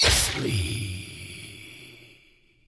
SH_die3.ogg